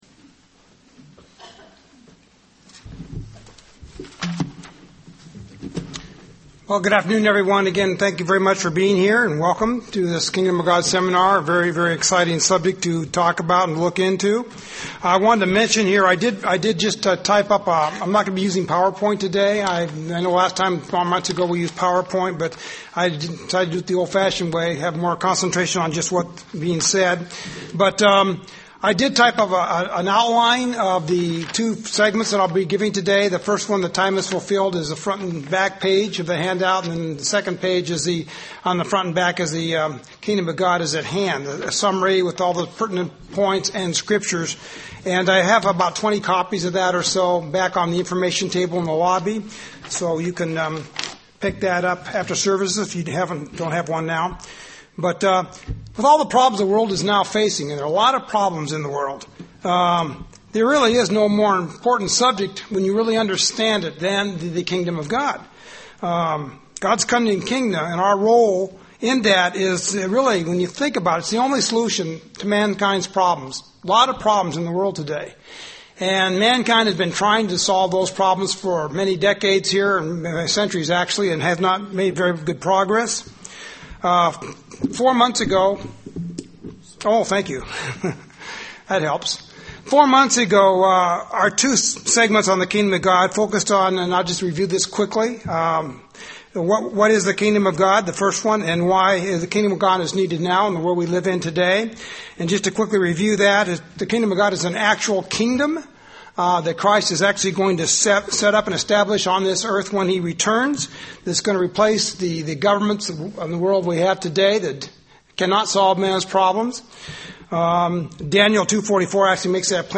Learn more in this Kingdom of God seminar.
UCG Sermon Transcript This transcript was generated by AI and may contain errors.